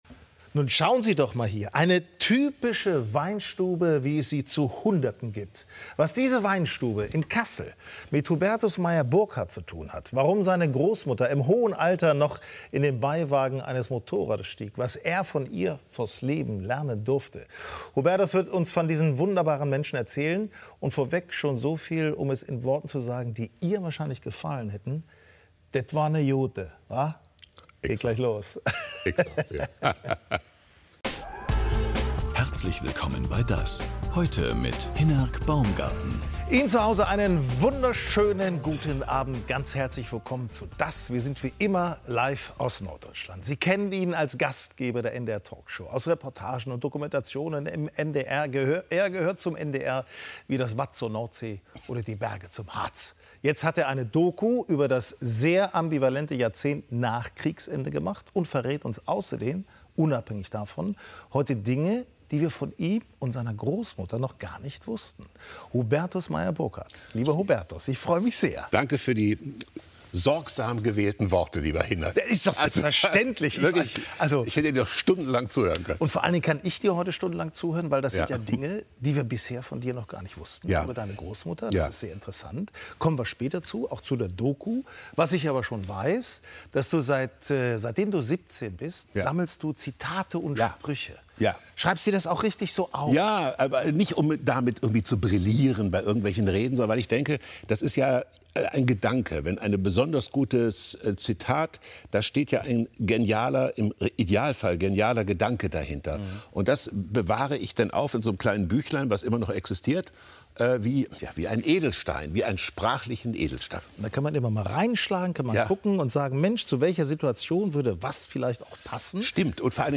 DAS! ist bekannt für intensive Interviews mit prominenten und kompetenten Gästen auf dem Roten Sofa. Die Gesprächsfassung (ohne Filmbeiträge) vom Vortag gibt es auch als Audio-Podcast.